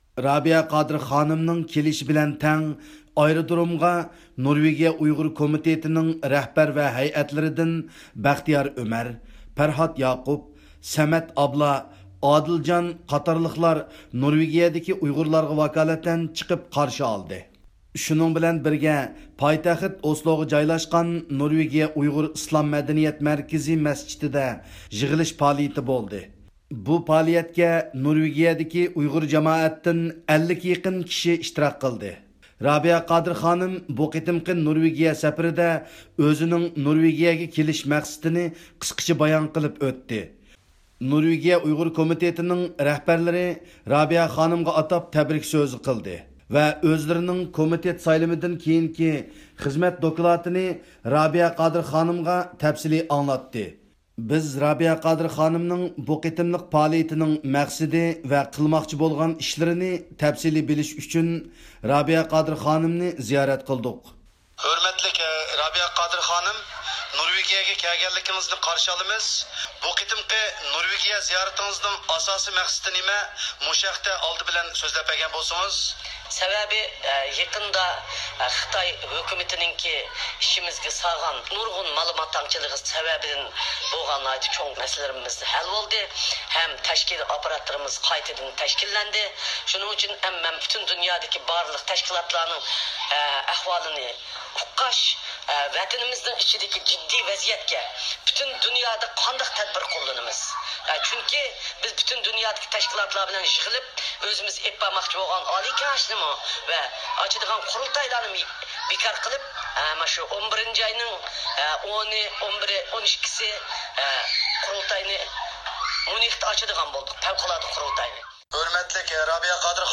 بىز رابىيە قادىر خانىمنىڭ بۇ قېتىملىق پائالىيىتىنىڭ مەقسىتى ۋە قىلماقچى بولغان ئىشلىرىنى تەپسىلىي بىلىش ئۈچۈن رابىيە قادىر خانىمنى زىيارەت قىلدۇق.